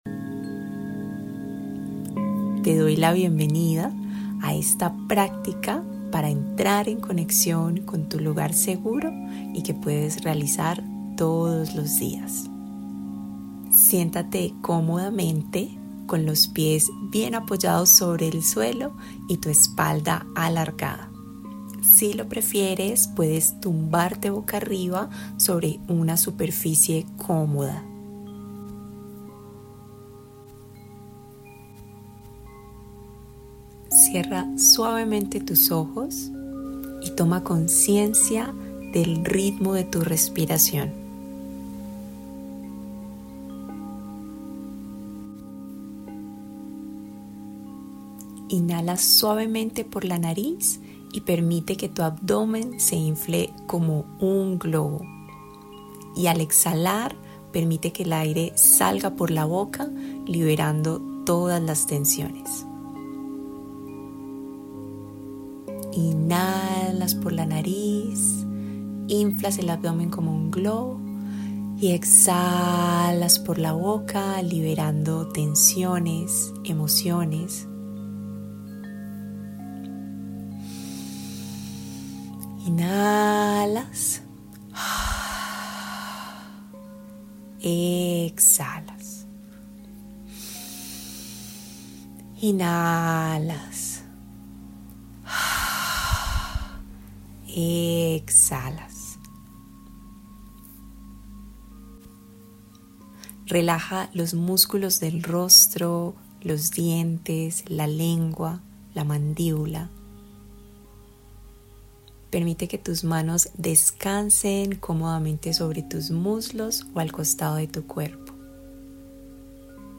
Tu Lugar Seguro💗 Haz una pausa en tus labores para reconectar con tu Paz & tu Claridad con esta práctica guiada de 10 minutos.